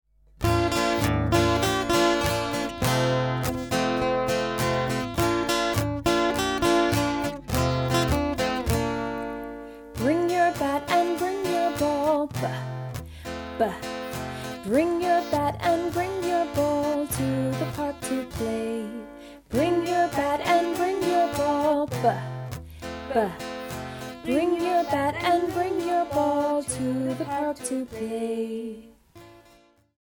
Action and Song: ถามนักเรียนว่า มีใครจำพี่บูมกับบาสได้บ้าง และเวลาพวกเขาฝึกลูกบอลเขาทำท่าอย่างไรบ้าง จากนั้นเรียนรู้เพลงประจำตัวอักษรเเละท่าทางของตัวอักษร b ครูชวนนักเรียนทำท่าทางของตัวอักษร b 2 รอบ ครูกดเล่นวิดีโอท่าทางให้นักเรียนดู 2 รอบ และเล่นเพลงให้นักเรียนฟัง พร้อมทำท่าทางประกอบ  คำแปลเพลง